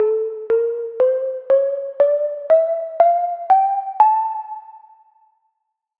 Spanish 8-Tone ♭/♮
2025-kpop-scale-spa8.mp3